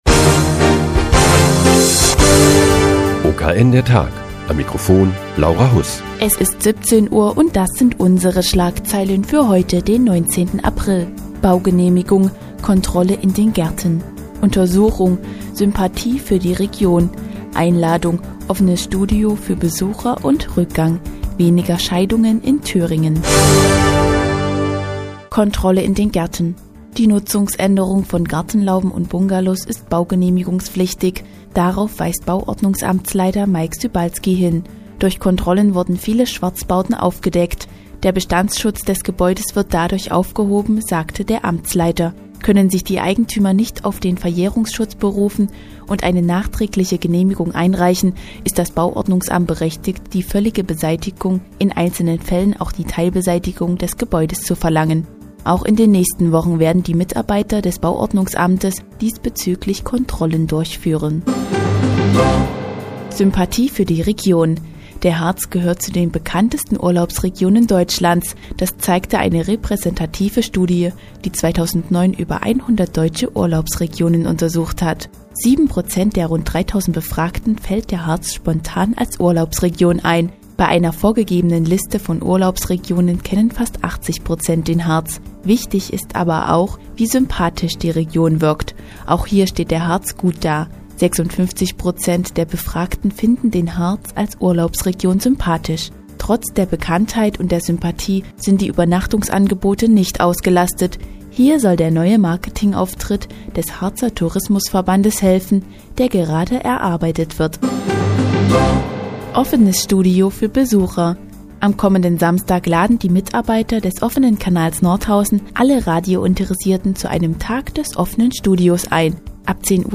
Die tägliche Nachrichtensendung des OKN ist nun auch in der nnz zu hören. Heute geht es um den Harz als sympathische Urlaubsregion und den "Tag des offenen Studios" am Samstag im OKN.